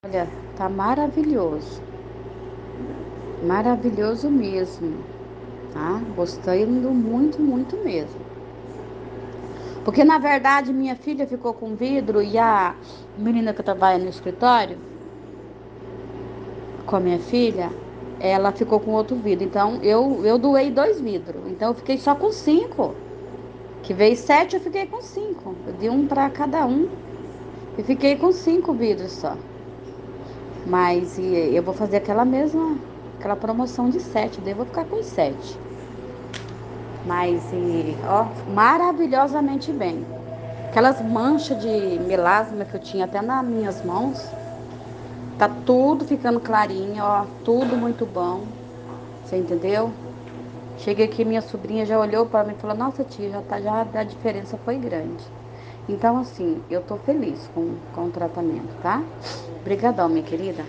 Depoimentos de quem usa: